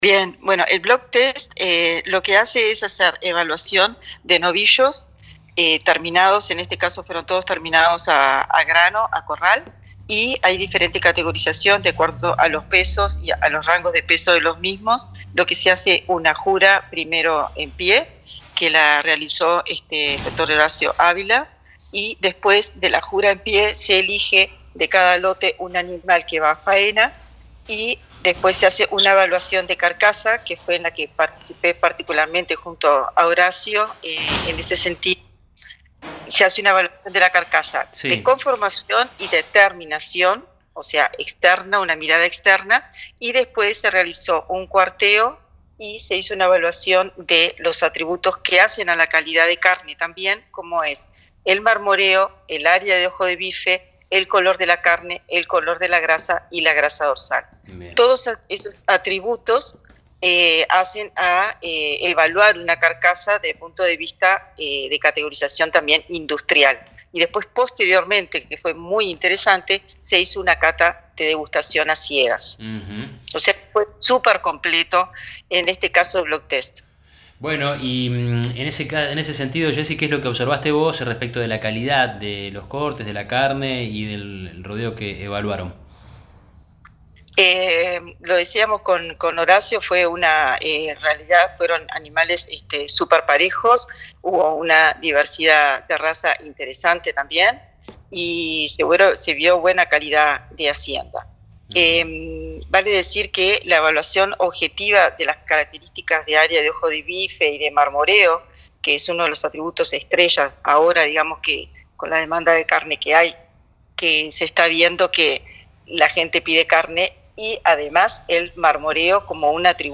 En diálogo con El Campo Hoy, la especialista uruguaya explicó que este proceso incluye una jura en pie y una evaluación de carcasa, que abarca aspectos como la conformación, el marmoreo, el área de ojo de bife y otros atributos que determinan la calidad de la carne.